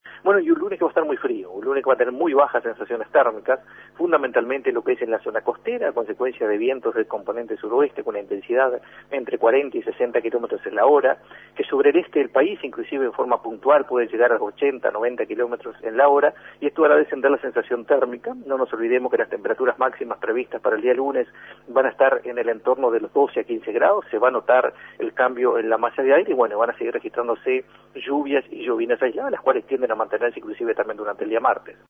Pronóstico 25 de agosto